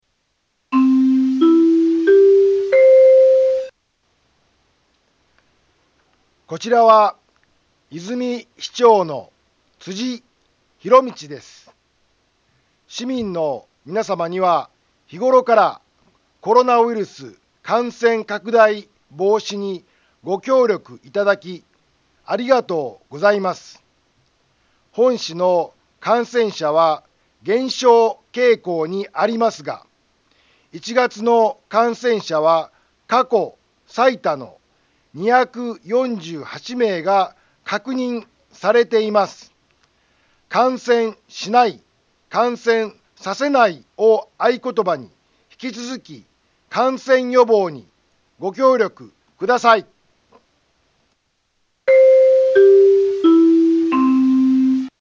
Back Home 災害情報 音声放送 再生 災害情報 カテゴリ：通常放送 住所：大阪府和泉市府中町２丁目７−５ インフォメーション：こちらは、和泉市長の辻 ひろみちです。